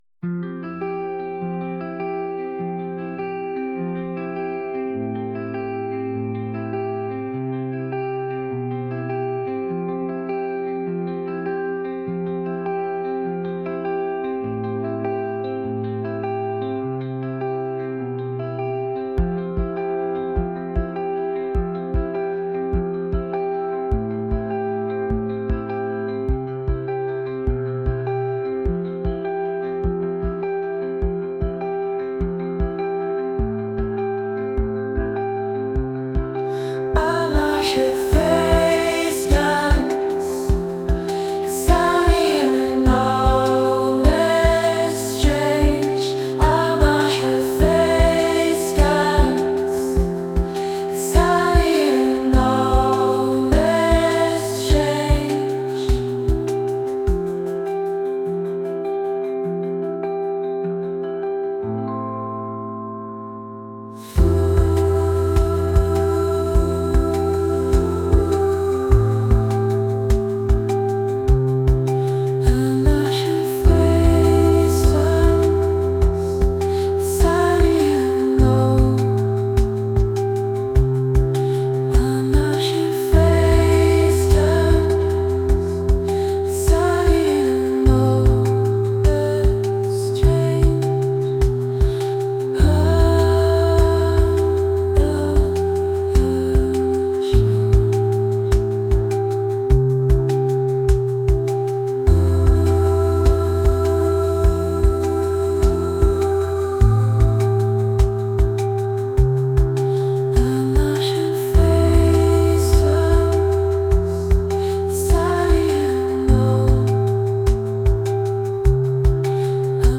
indie | pop | acoustic